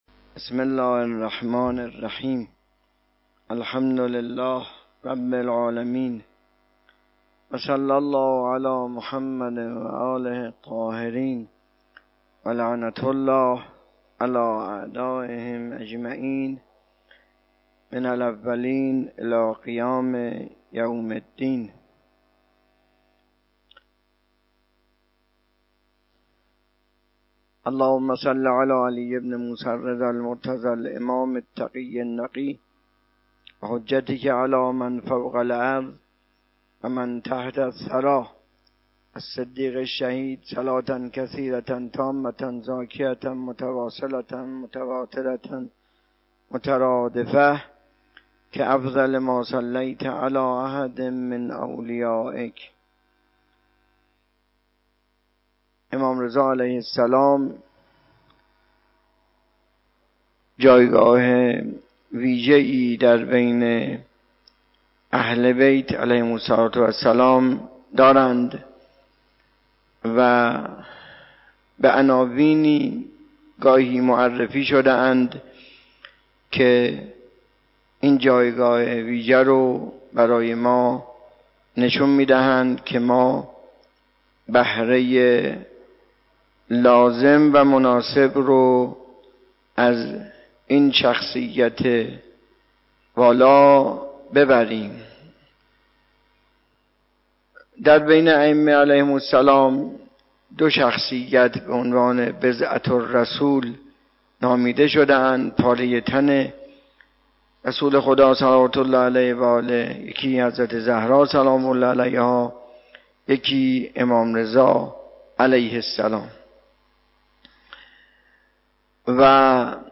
خیمه گاه - آیت الله محمد باقر تحریری - سخنرانی به مناسبت ولادت امام رضا(علیه السلام)